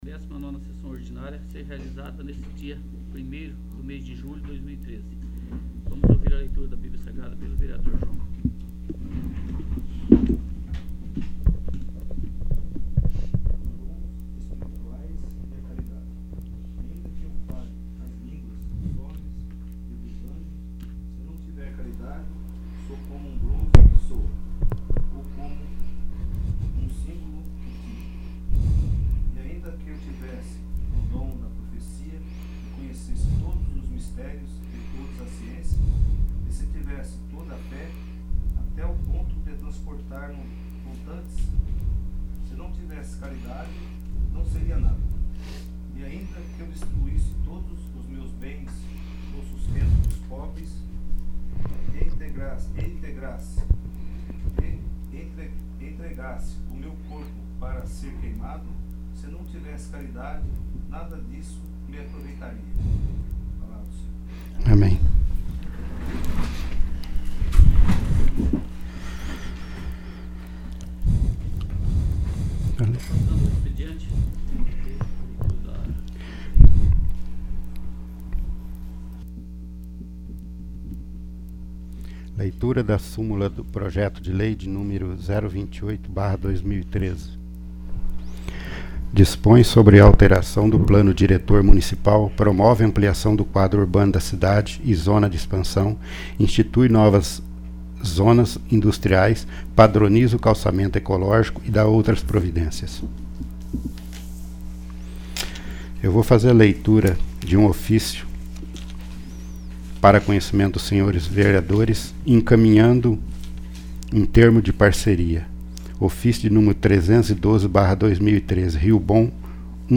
19º. Sessão Ordinária